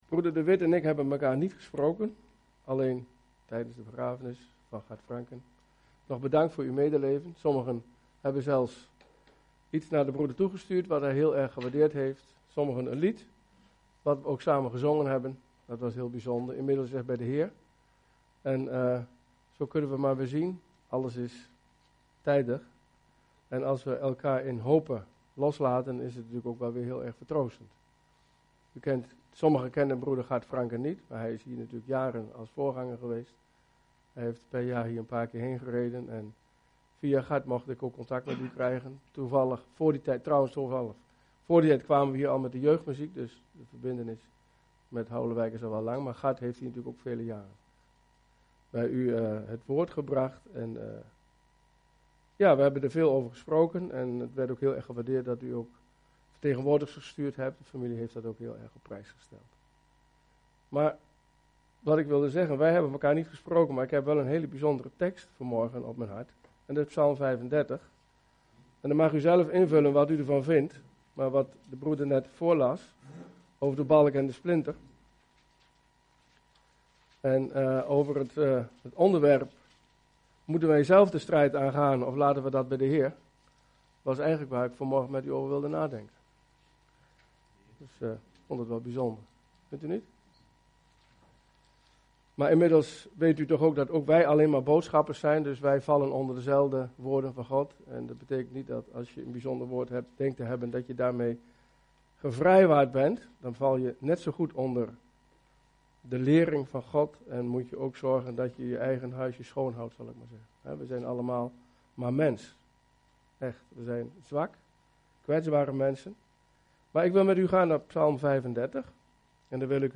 Over deze preek